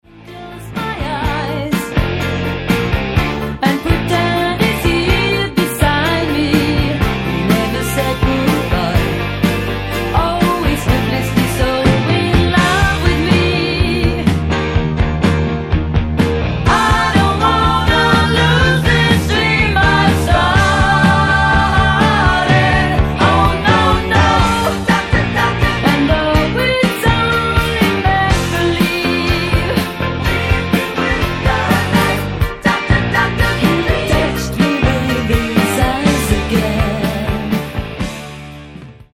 LightMellow